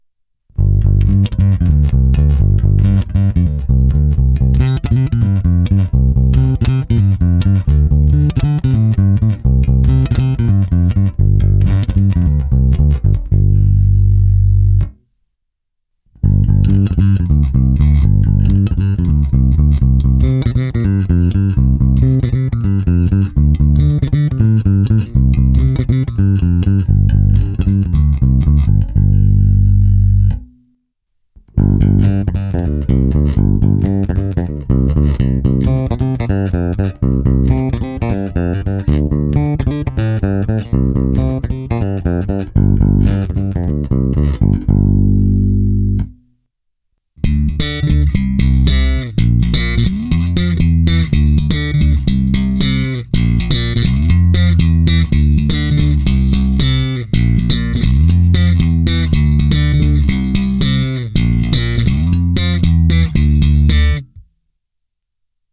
Kvůli větší představě o tom, jak hraje baskytara přes aparát jsem basu prohnal preampem Tech 21 SansAmp VT Bass Deluxe (recenze)
Ukázka ve stejném pořadí snímačů jako výše + slap